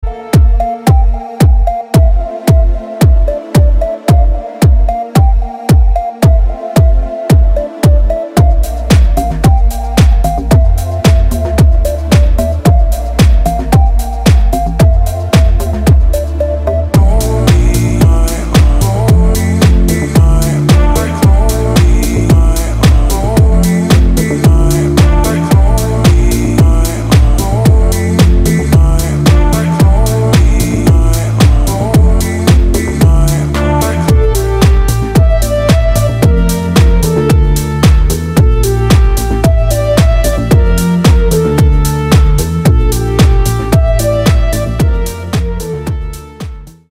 мужской голос
deep house
атмосферные
спокойные
красивая мелодия
Electronica
Downtempo